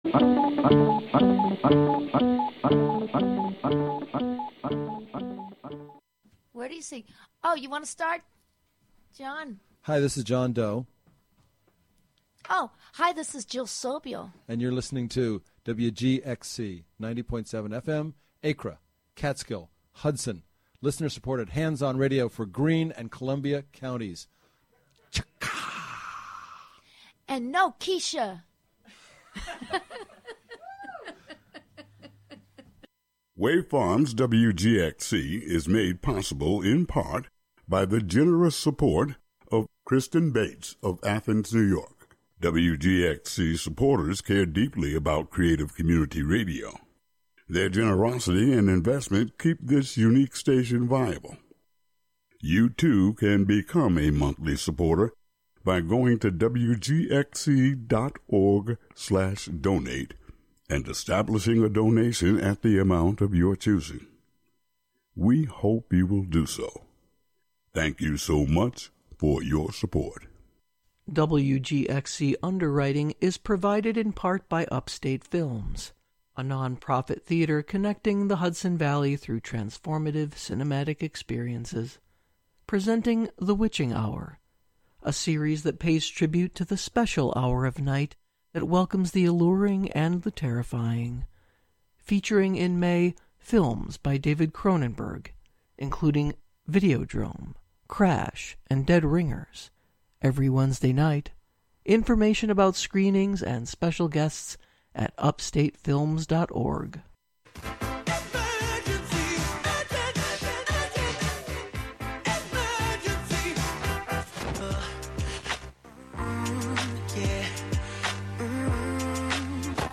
Live from Brooklyn, New York